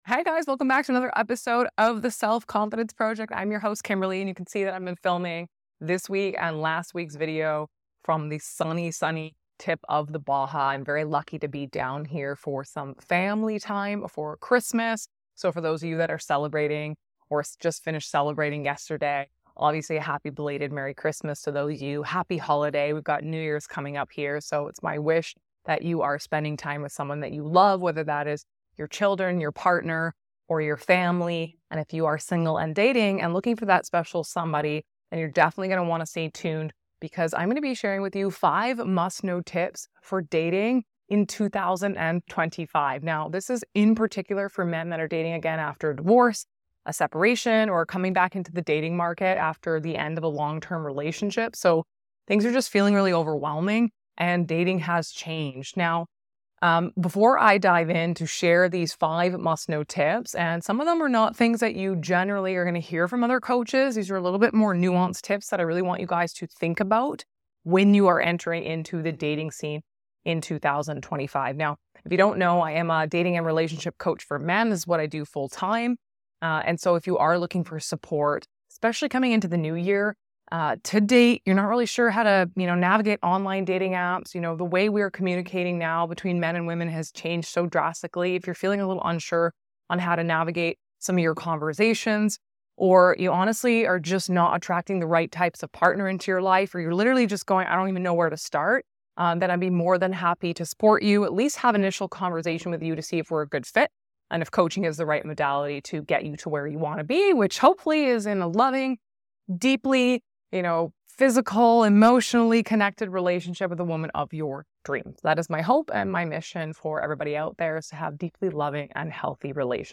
I'm filming from the sunny tip of the Baja as I share my top five tips for men dating in 2025, especially those reentering the dating scene post-divorce or after a long-term relationship.